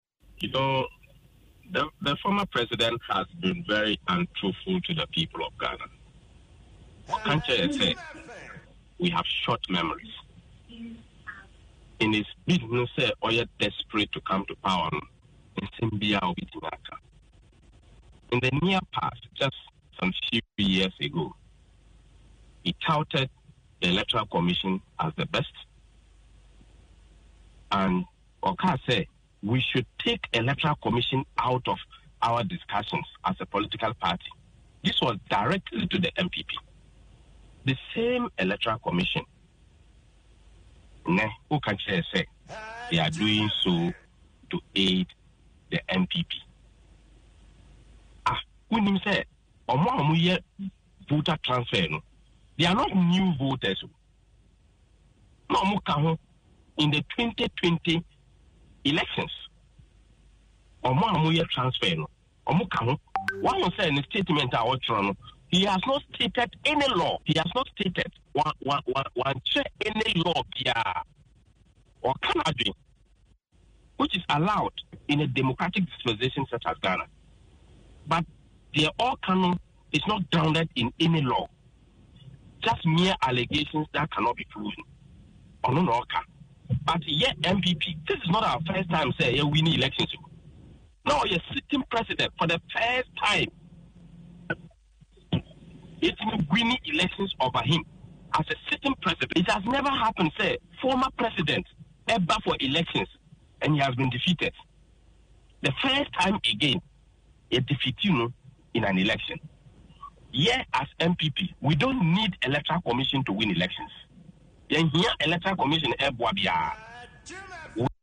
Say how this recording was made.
Reacting to the comments on Adom FM’s morning show.